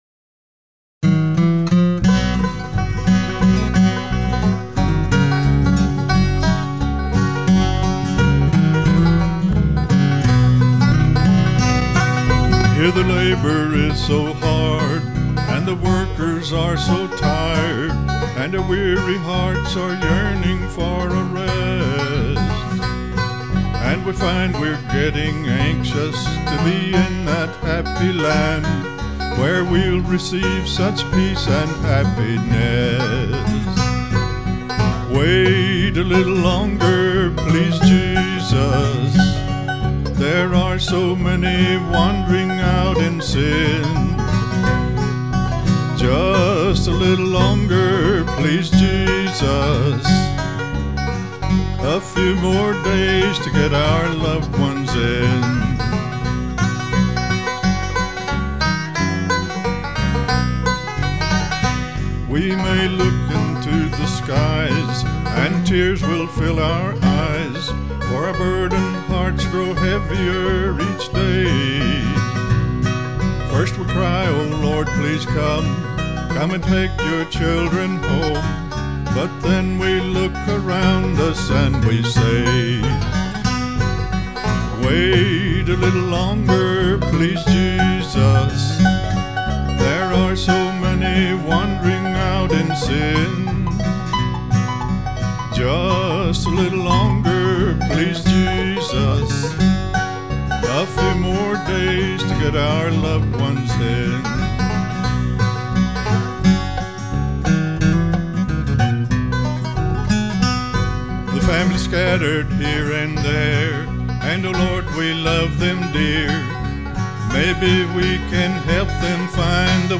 all gospel